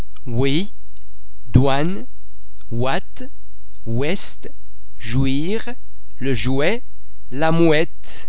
oi oê oe oy [Wa][ typically French sound]
·ou+vowel
wa_oui.mp3